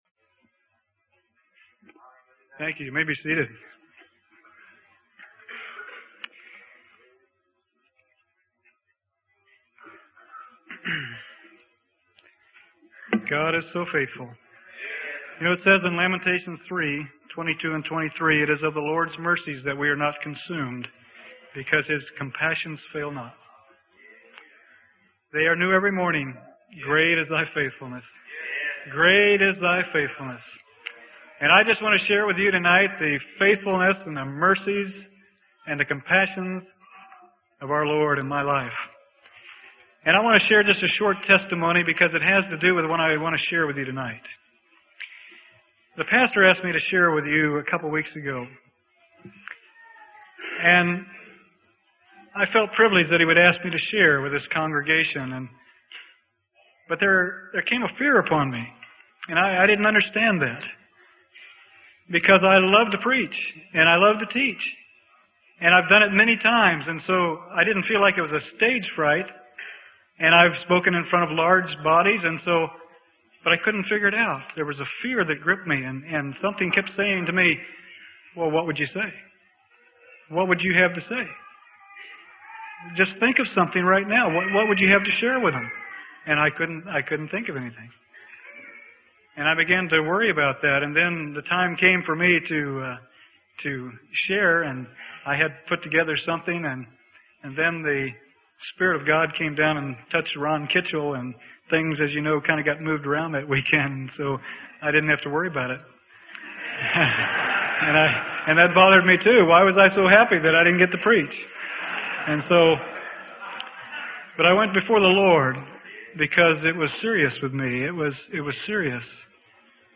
Sermon: The Way of Man is Not Within Himself - Freely Given Online Library